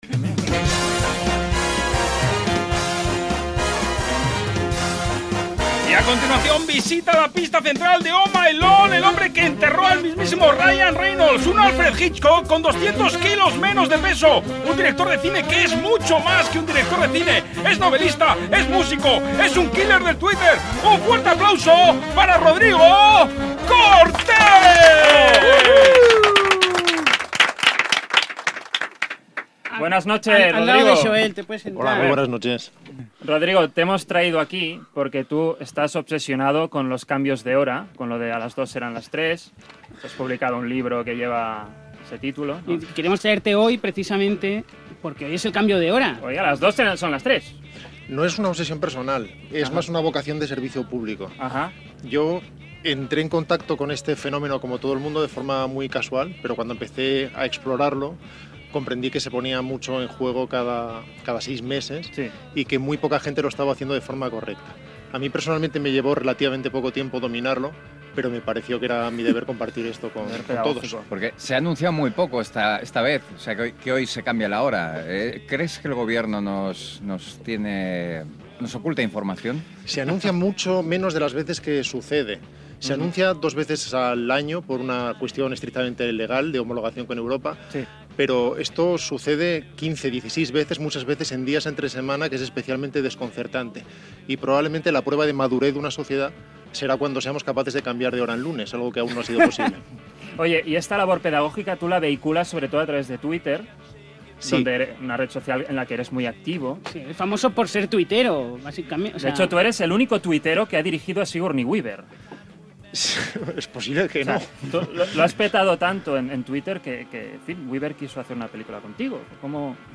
Diàleg amb el director i actor Rodrigo Cortés sobre el canvi d'hora i al final aquest imita el so d'una gaita amb la seva veu
Entreteniment